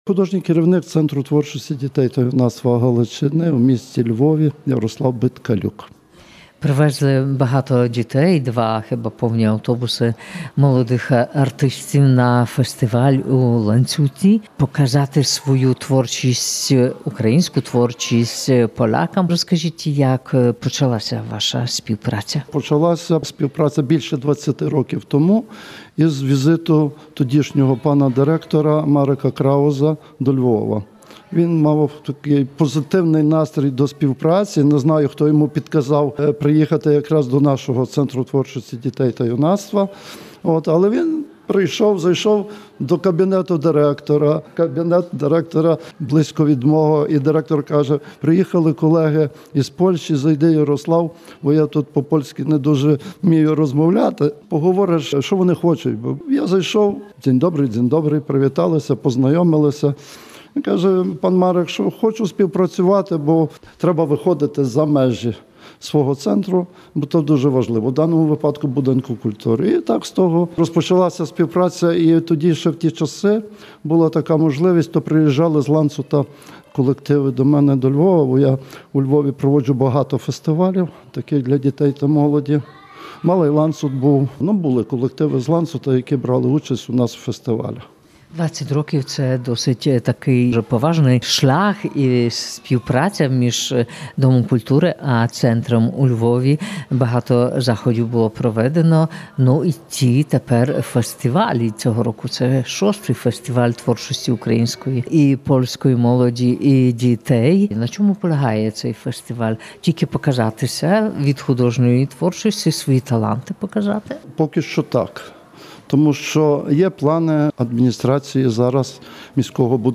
Вже кілька років поспіль дитячі колективи із західної України виступають в Ланцуті .
25.05-SKRYNIA-LANCUT-FESTIWAL.mp3